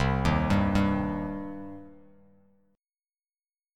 Cm Chord
Listen to Cm strummed